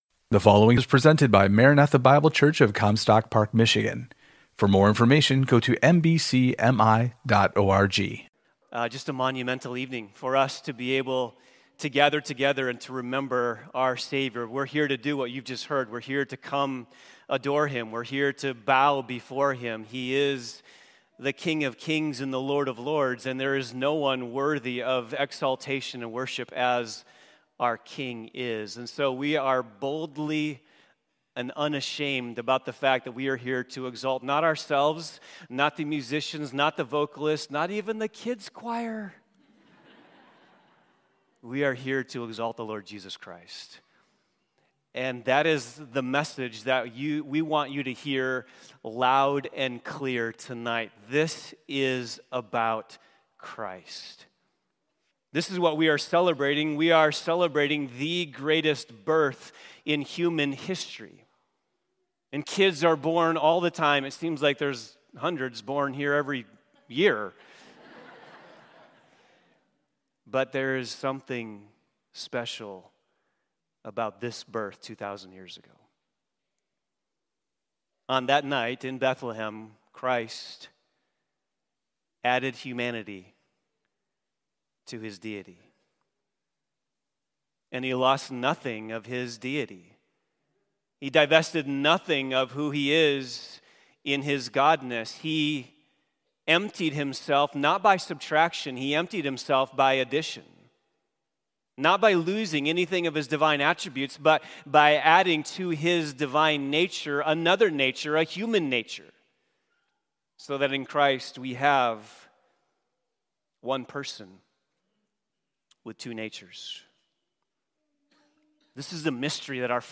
Christmas Sermon